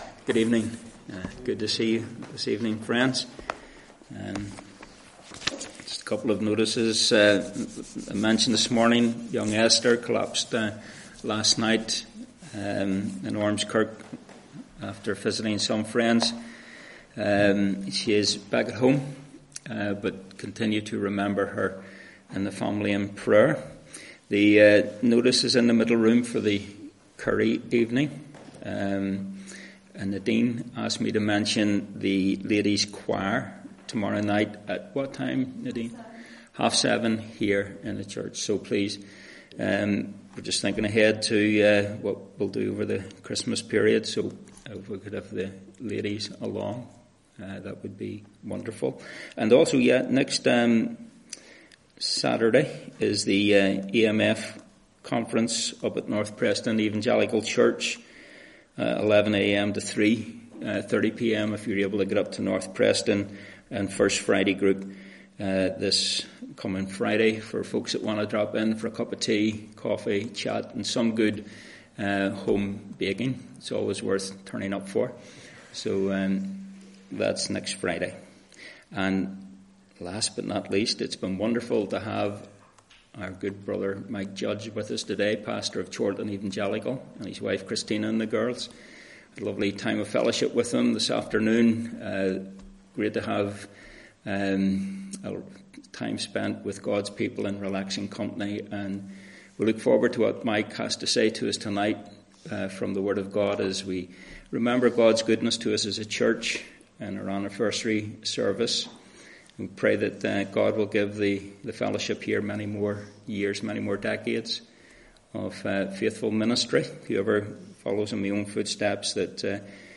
Sunday 31st October 2021 (pm) (Church Anniversary Weekend)